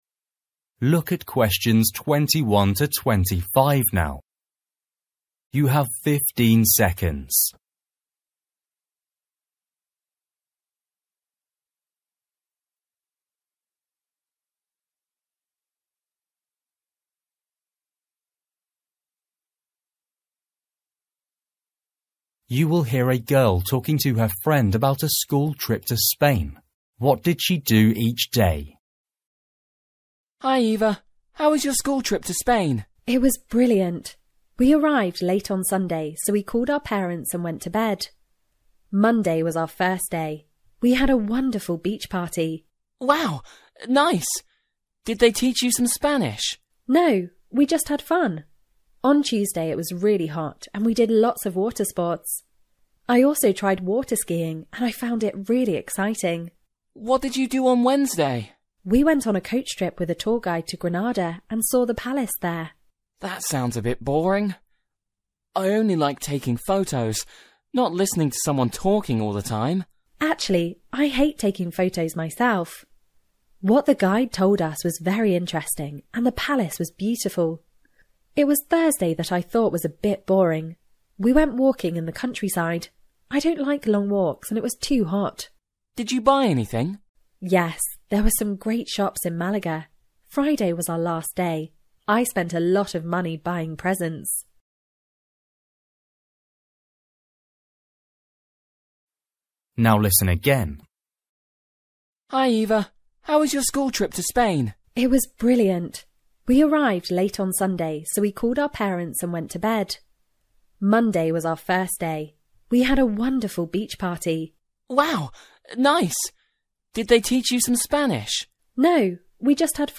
You will hear a girl talking to her friend about a school trip to Spain.